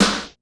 DrSnare2.wav